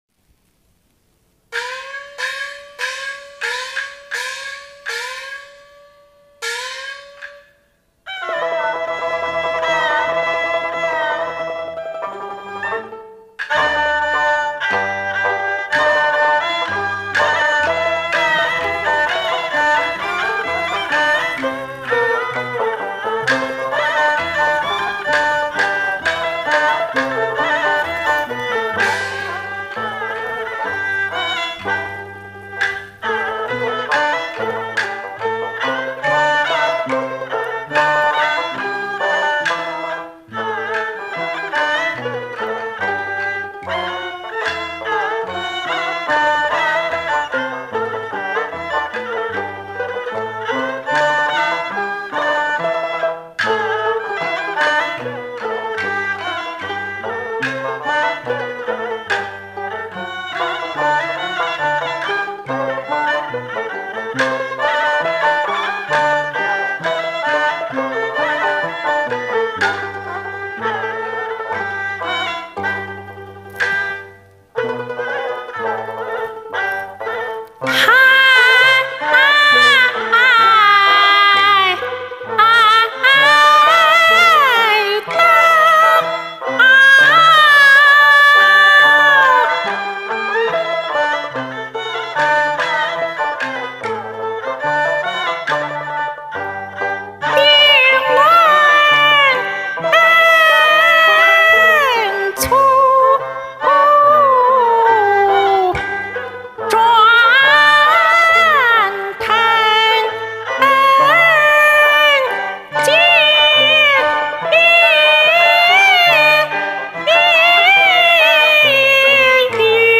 全民合成的時候聽着是對齊的（只有個別幾處是我自己走板了），但發布以後不知為什麼老是對不齊。